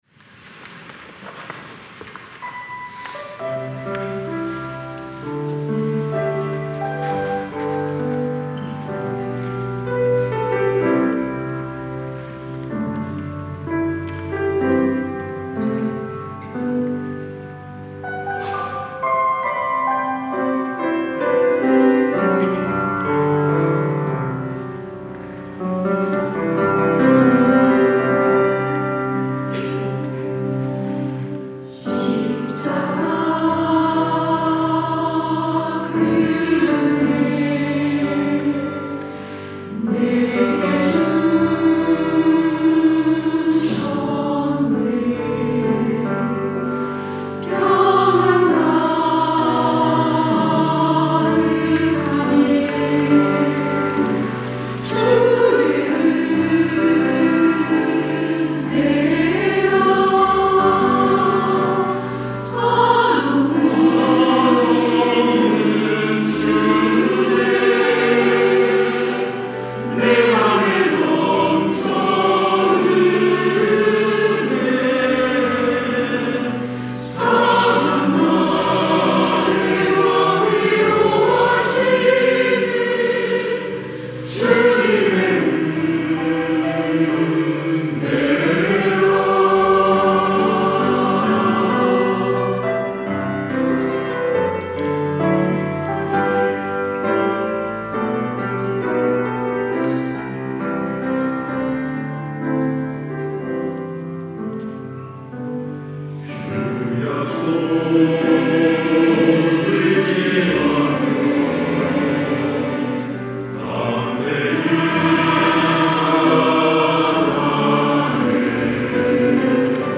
시온(주일1부) - 은혜 위에 은혜
찬양대